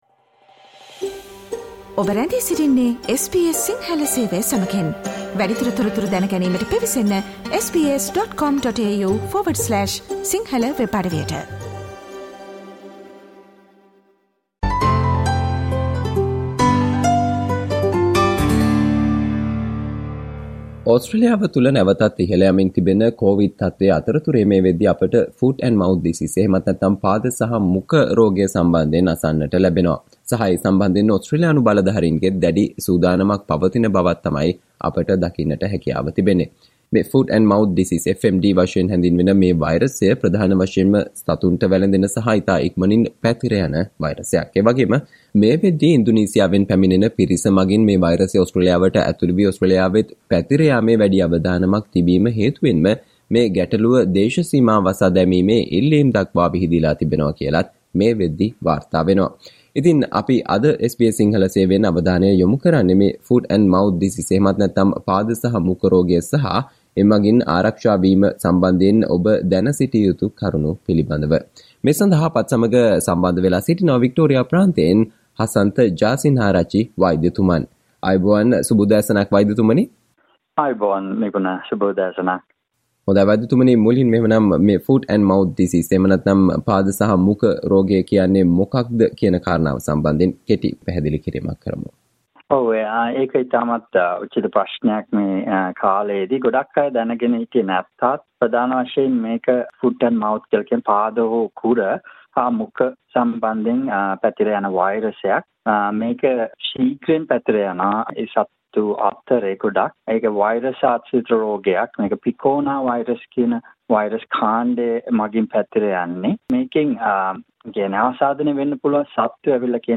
මේ වනවිට ලොව විවිධ රටවල පැතිර යන සහ ඕස්ට්‍රේලියාවටත් ඇතුලුවීමේ අවදානමක් මතුව ඇති Foot-and-mouth disease නොහොත් "මුඛ සහ පාද" රෝගය සම්බන්ධයෙන් SBS සිංහල සේවය සිදු කල සාකච්චාවට සවන් දෙන්න